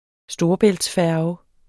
Udtale [ ˈsdoːʌbεlds- ]